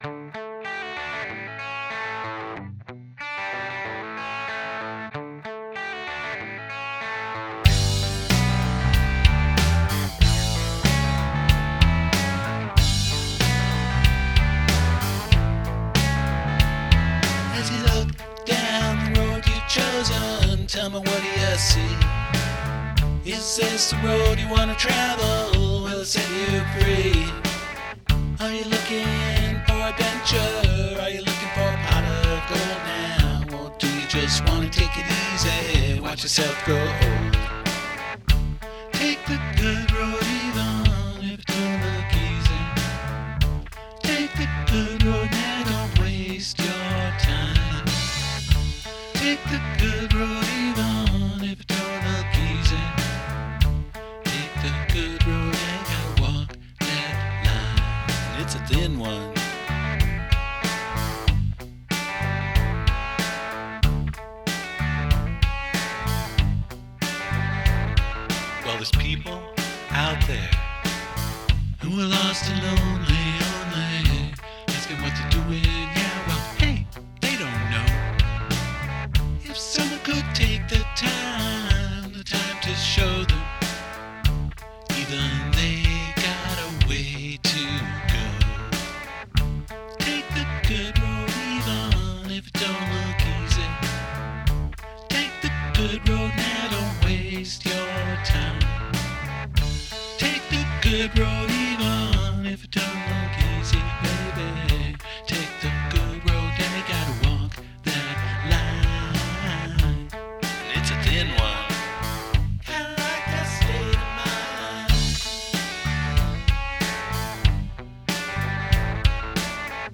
a little more upbeat
a country-rock flavor